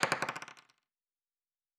pgs/Assets/Audio/Fantasy Interface Sounds/Dice Single 9.wav at master
Dice Single 9.wav